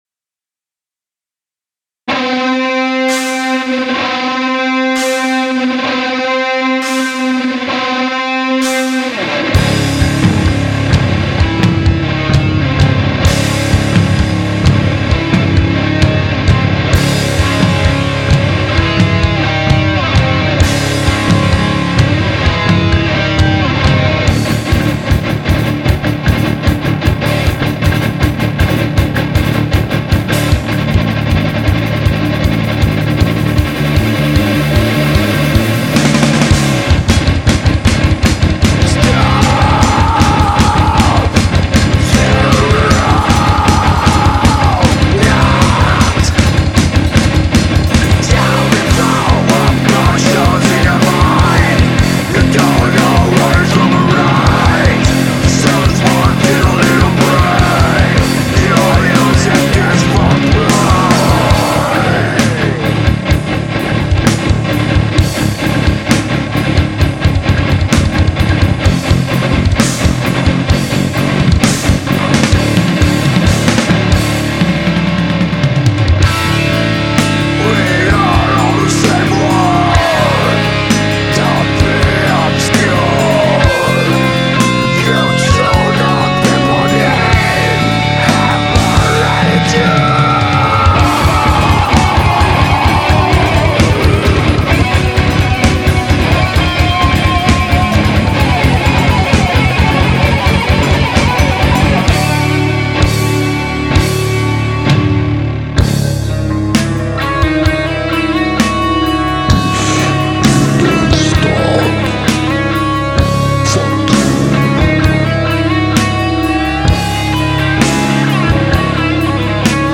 guitarra solo